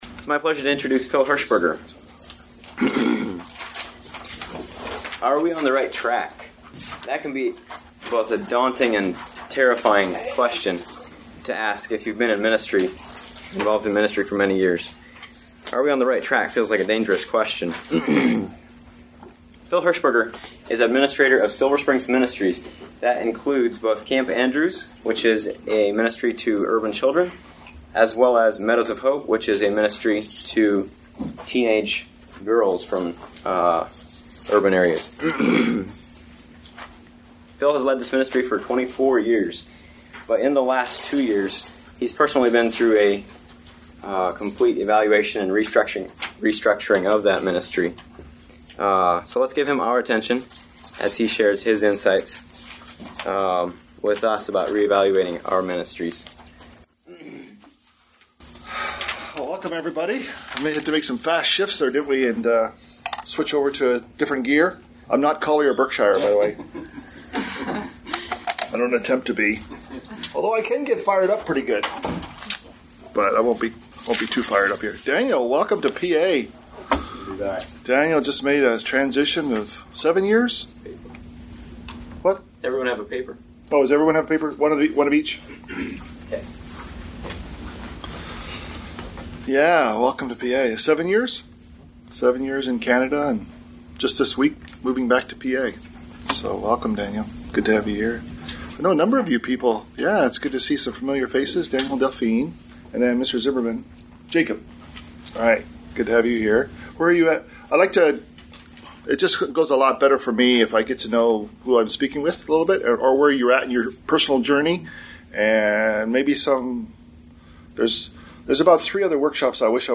Home » Lectures » Reevaluating Your Ministry